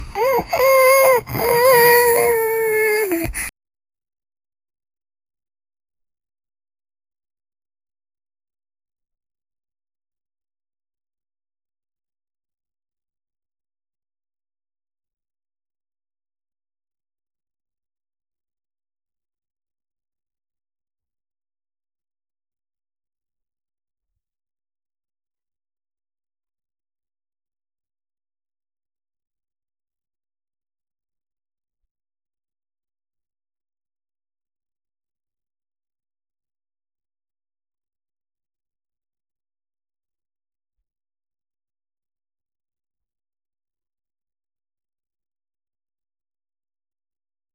A realistic baby crying softly with emotional pain, trembling voice, short breath, sobbing between cries, feeling scared and lonely, heart-touching, natural baby voice, no music, pure raw emotion, cinematic realism
-a-realistic-baby-crying-q5dt6ry4.wav